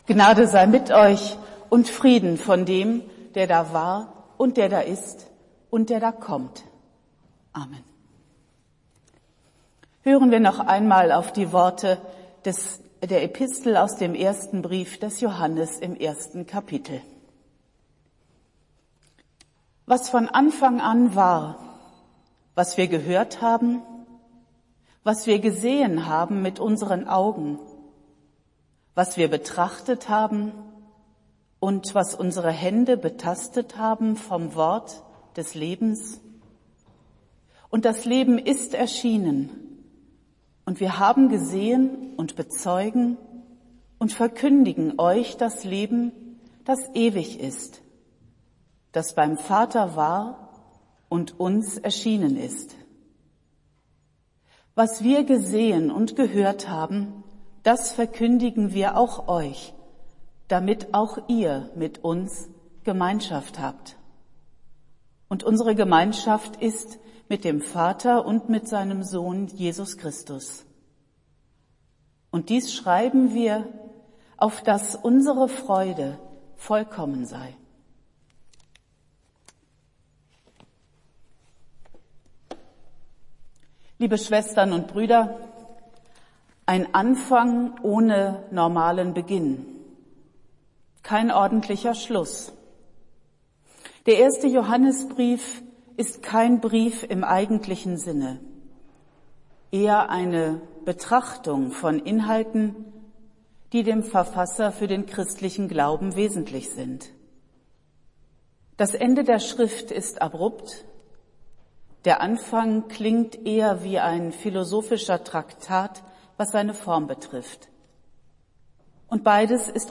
Predigt des Gottesdienstes aus der Zionskirche vom Sonntag, 02.01.2022